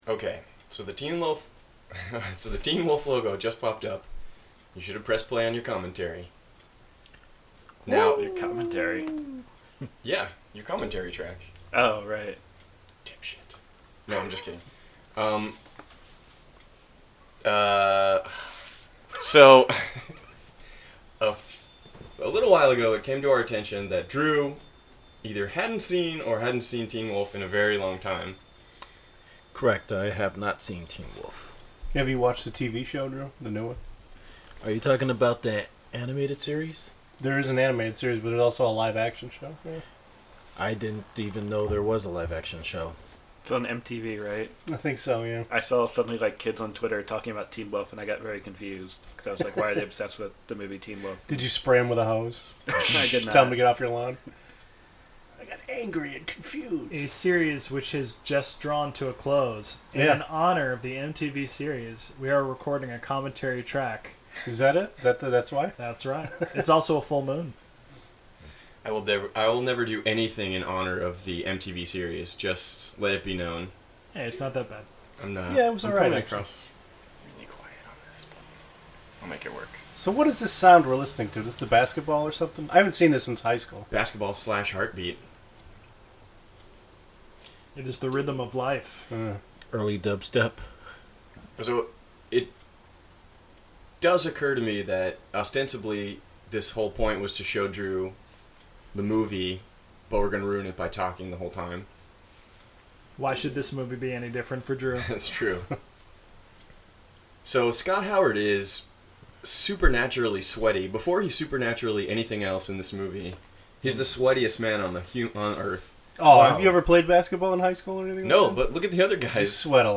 Teen Wolf Commentary Track
If you’d like to synchronize our commentary with the film, be sure to start the podcast when the Teen Wolf logo appears.